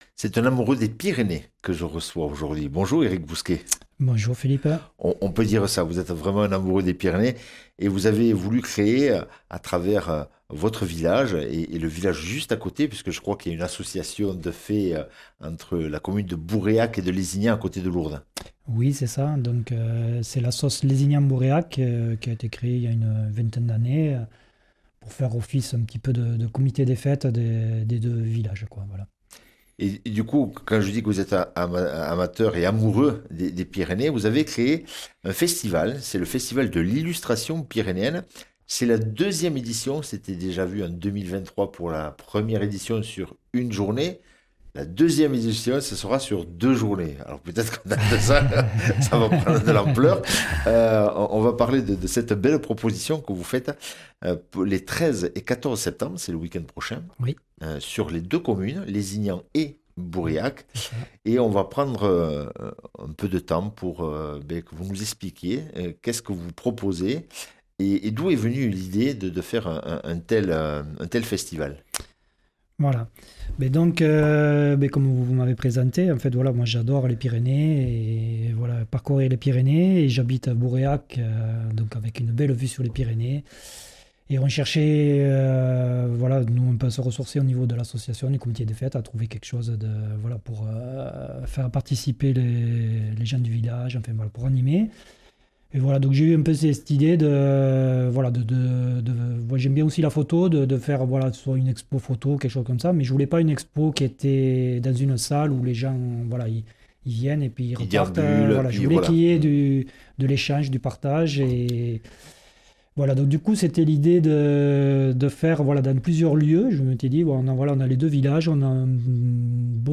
Interview et reportage du 12 sept.